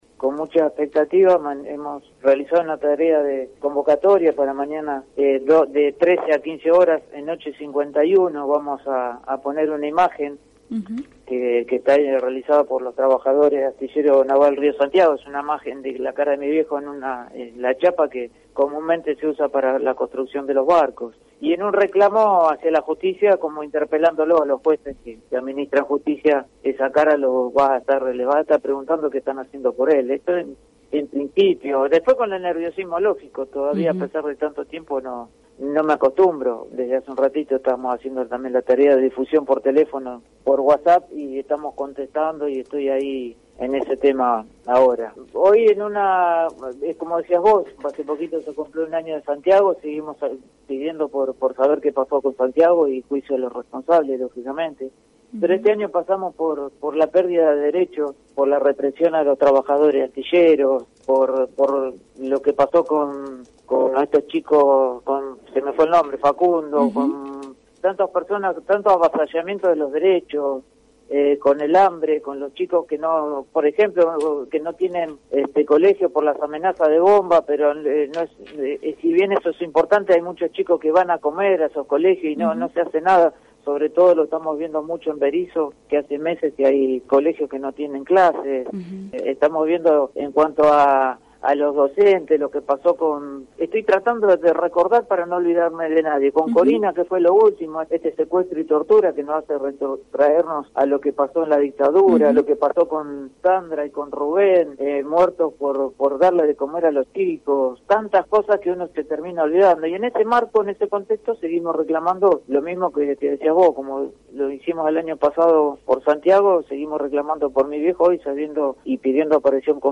En diálogo con Caídas del Catre